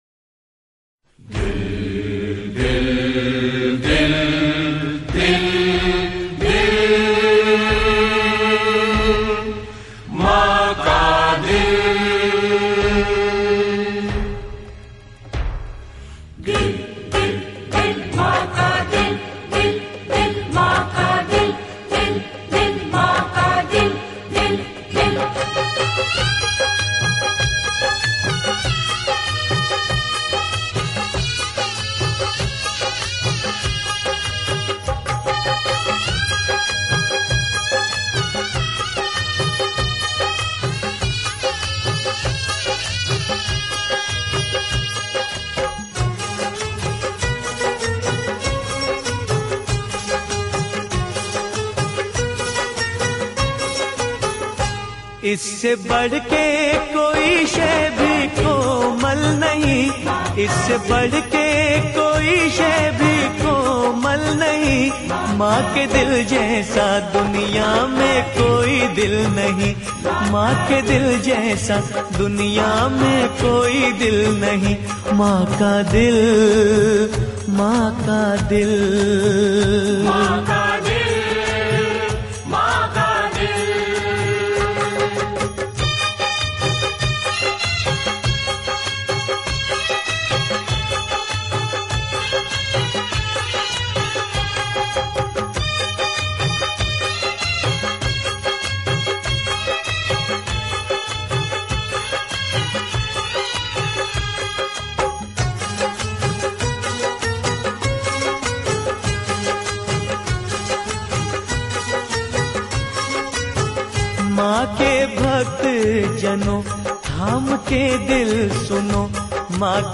Bhakti Sangeet